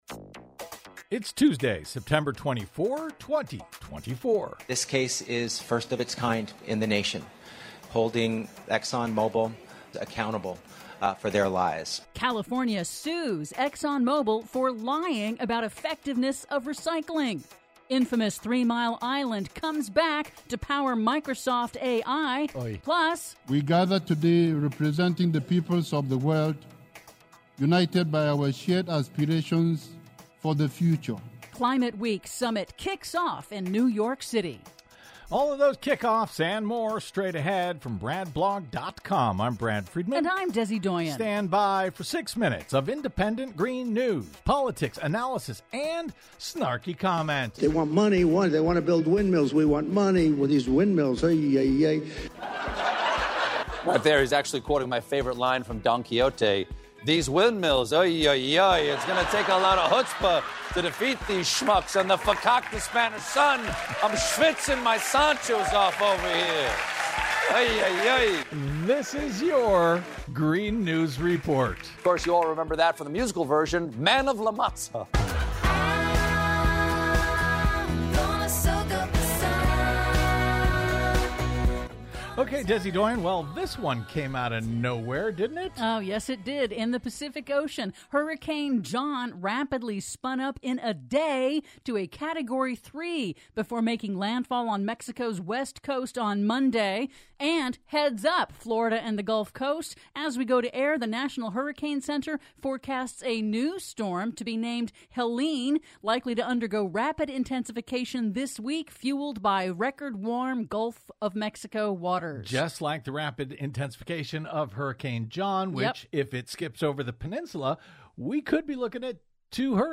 IN TODAY'S RADIO REPORT: California sues ExxonMobil for lying about effectiveness of plastic recycling; Hurricane John strikes Mexico as a surprise Category 3; Infamous Three Mile Island coming back to power Microsoft's A.I.; PLUS: Climate Week summit kicks off in New York City... All that and more in today's Green News Report!